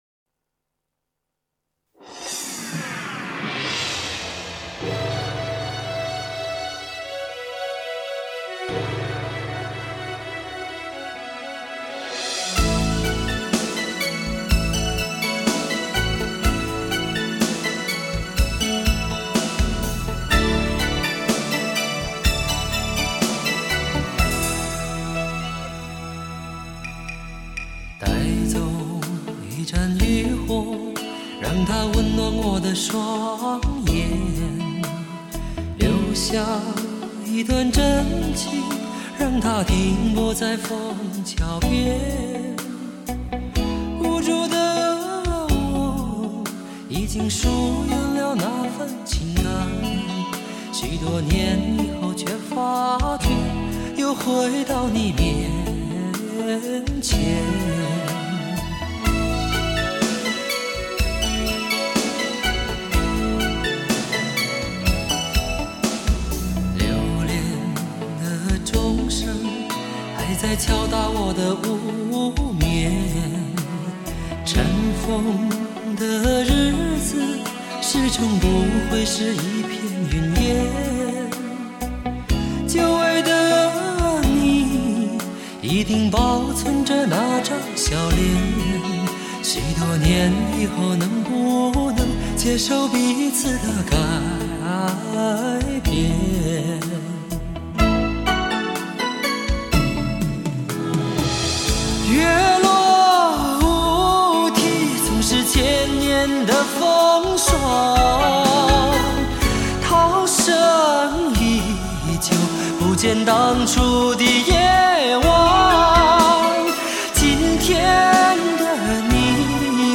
这种植入使得这首歌曲如同唐朝的绝句诗，显得清雅质朴而富于韵味。